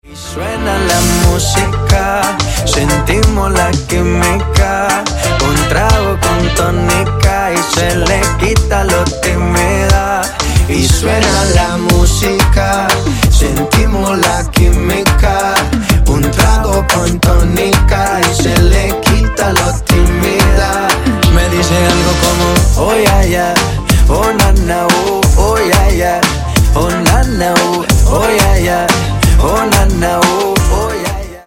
Латинские Рингтоны
Поп Рингтоны